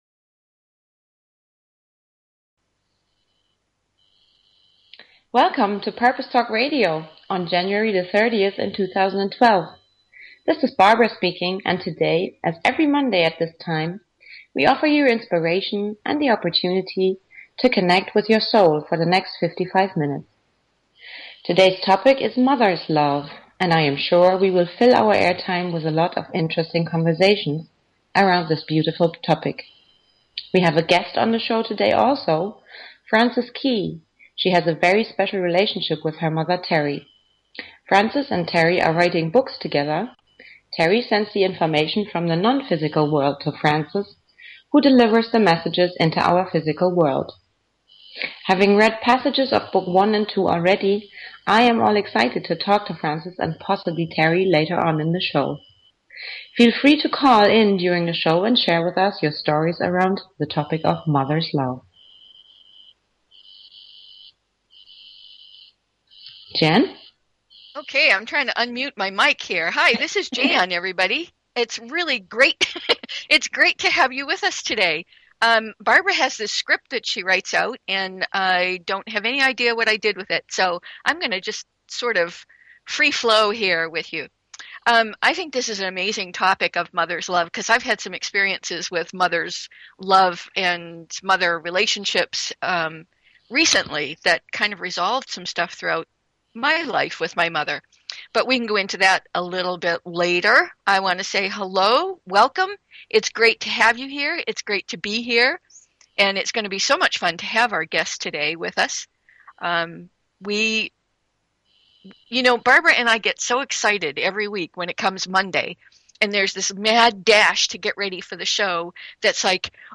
Talk Show Episode, Audio Podcast, Purpose_Talk_Radio and Courtesy of BBS Radio on , show guests , about , categorized as